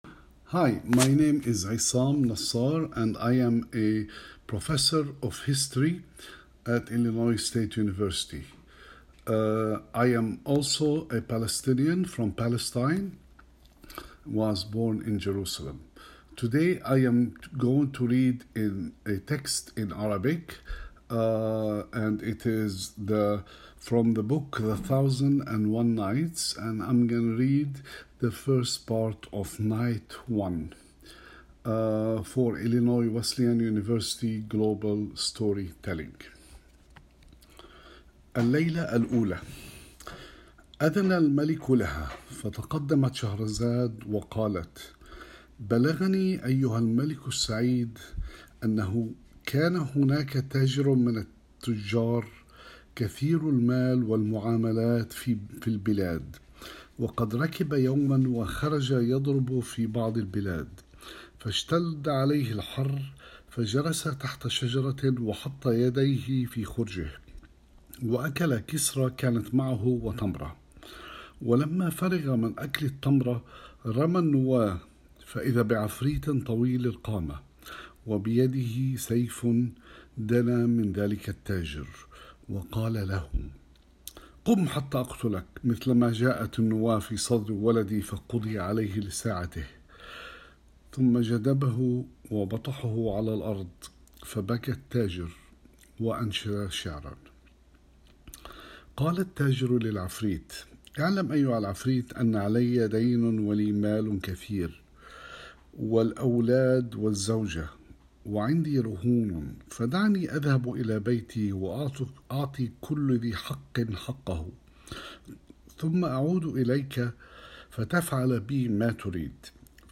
About the Arabic language in Palestine: Palestinian Arabic is a dialect of Modern Standard Arabic spoken by approximately five million people in Palestine.